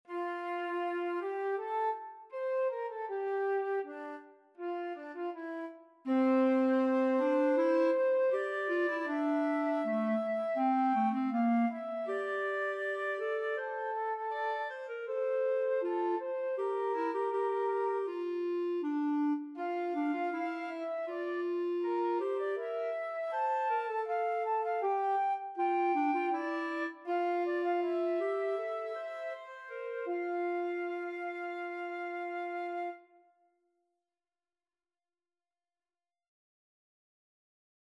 For this piece the theme consists of 12 notes, which can be seen in the first to bars for the flute.
The voice of the clarinet starts in bar 3 with the theme on the dominant 5th (C) and is followed by a variation starting on the major 2nd (G), which only finishes in bar 7. In bar 8 and 9 the theme can be heard in its original form one more time and the clarinet finishes the piece by playing a variation of the beginning of the theme.